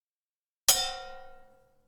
#5 Metal Bang
Bang Bonk Hit Impact Metal Metallic Thump sound effect free sound royalty free Memes